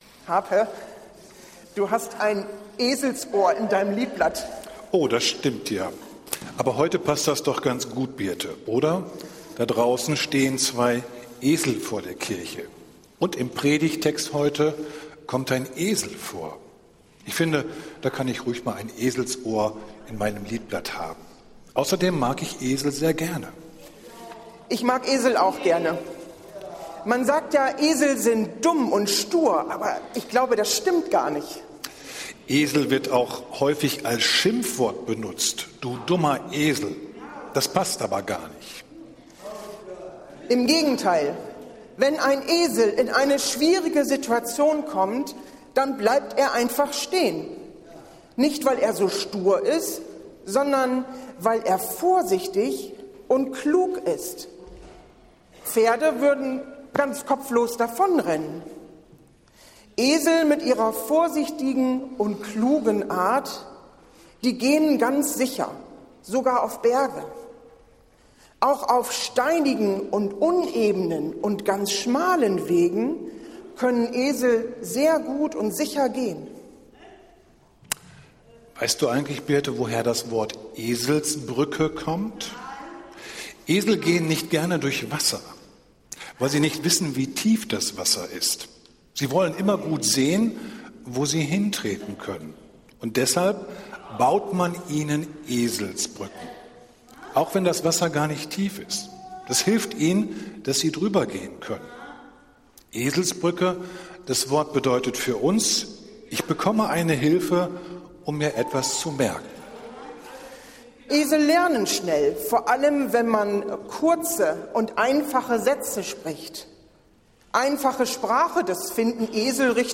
Es handelte sich um einen Gottesdienst in einfacher Sprache.